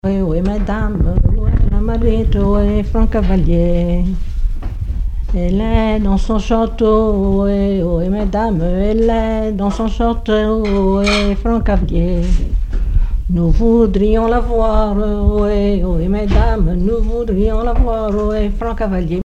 rondes enfantines
Chansons traditionnelles et populaires
Pièce musicale inédite